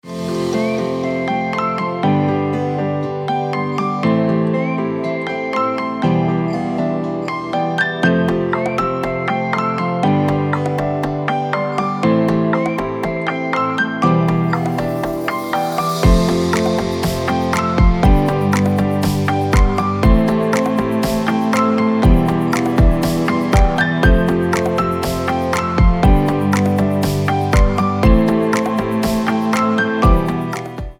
Приятная мелодия для будильника